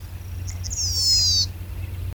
Caminheiro-zumbidor (Anthus chii)
Nome em Inglês: Yellowish Pipit
Localidade ou área protegida: Mburucuyá (localidad)
Condição: Selvagem
Certeza: Fotografado, Gravado Vocal